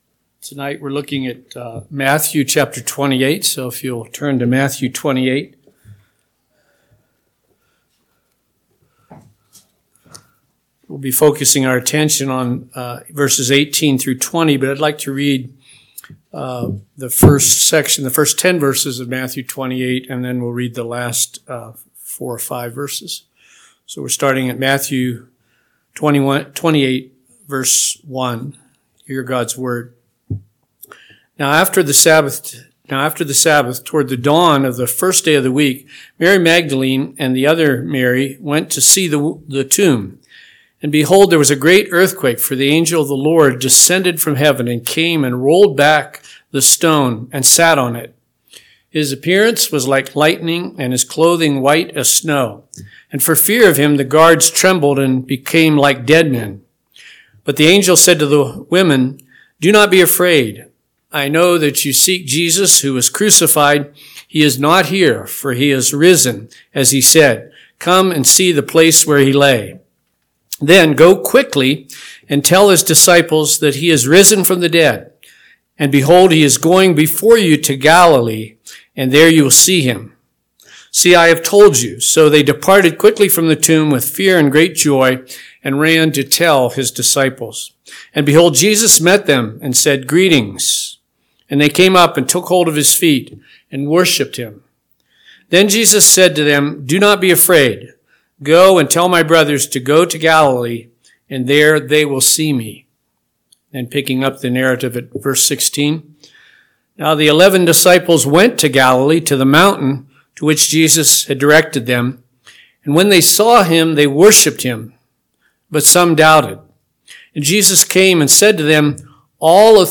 PM Sermon – 12/29/2024 – Matthew 28:18-20 – Northwoods Sermons